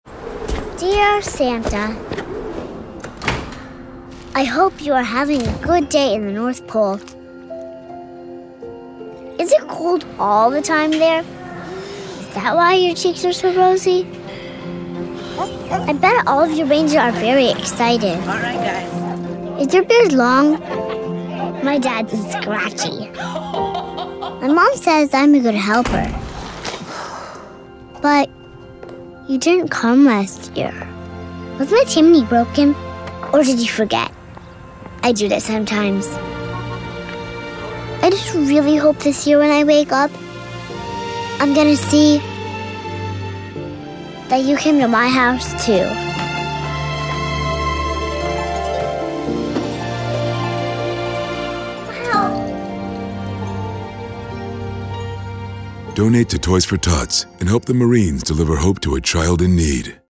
Toys for Tots Digital Media Library (Radio PSAs)